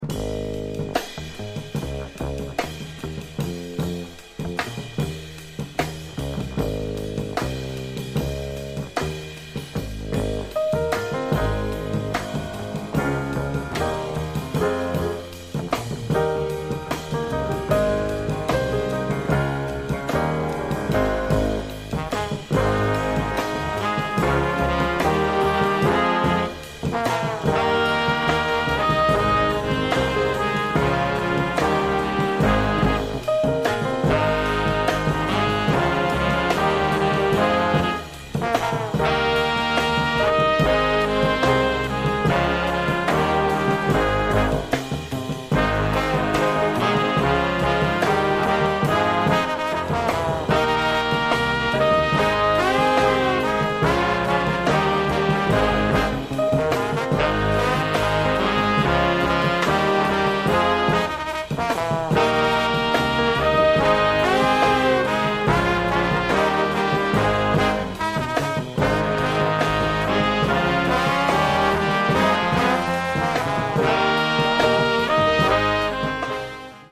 recorded in West London